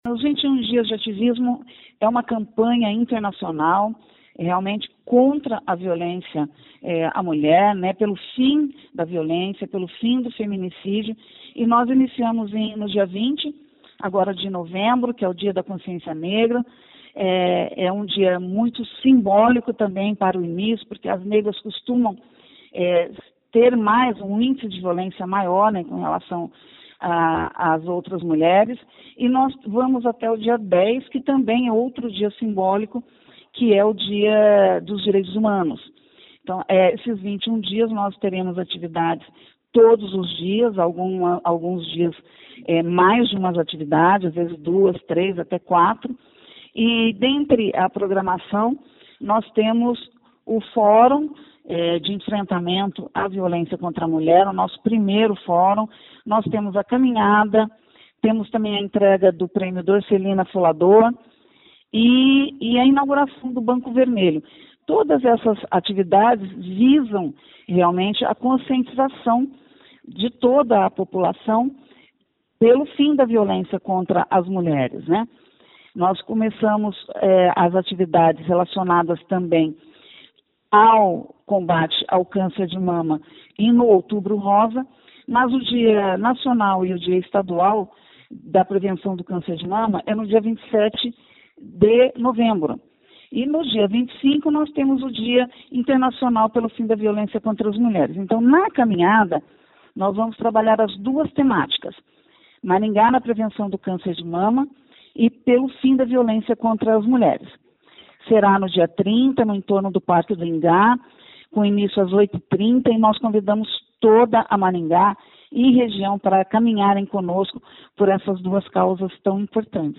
A secretária de Políticas Públicas para Mulheres de Maringá, Olga Agulhon, explica sobre a caminhada e sobre os “21 dias de ativismo”: